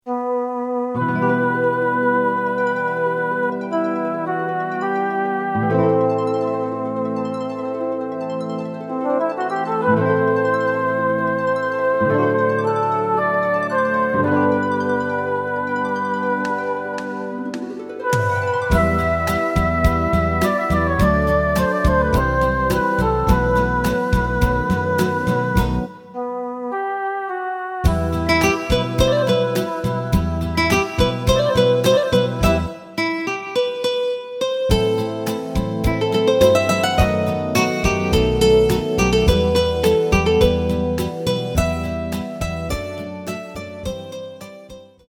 エレキバンド用カラオケCD製作・販売
すべての主旋律を１人で演奏するスタイルにアレンジしてみました。
●フルコーラス(デモ演奏) メロディライン＋伴奏が演奏されます。